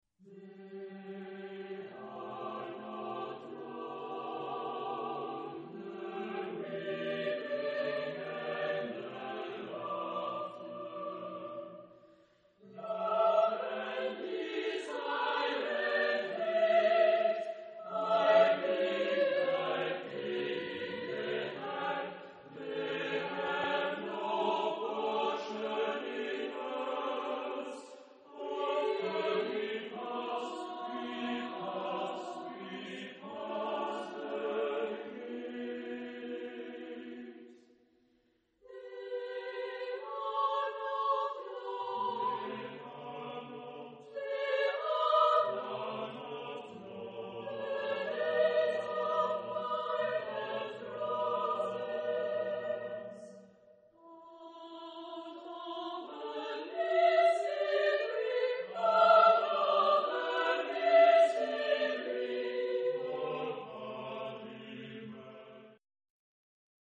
Genre-Style-Forme : contemporain ; Profane ; Chanson
Caractère de la pièce : cantabile ; léger
Type de choeur : SATB  (4 voix mixtes )